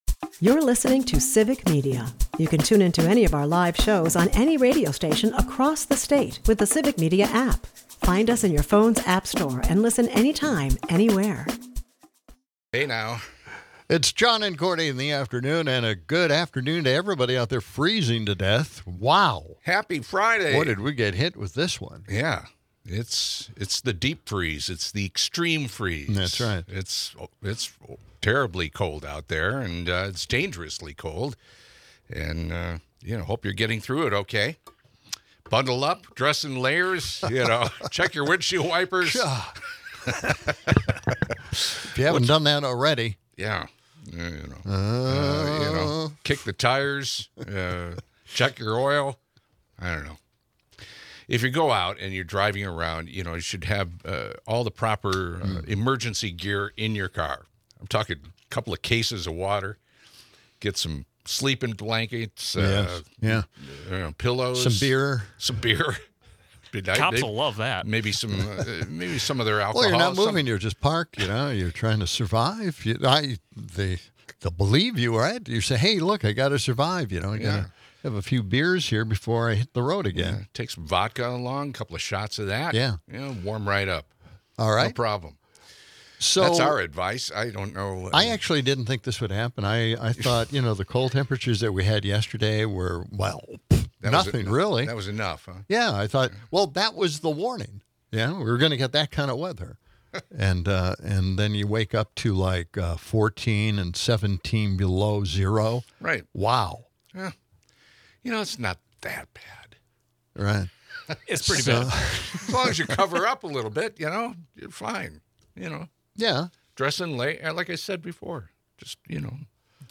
The main event is the heated discussion surrounding Jack Smith’s testimony and Glenn Grothman's bumbling questioning, exposing the chaotic aftermath of January 6th. Listeners call in with spirited takes on the fake elector plot and the Republicans' contradictory narratives. Amidst it all, the show sprinkles humor with cold weather antics and nostalgic rock lyric recitations, keeping spirits warm despite the frigid air.